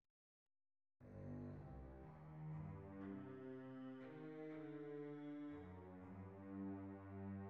The opening of Beethoven's Symphony No. 5, third movement is often used as an orchestral excerpt during bass auditions.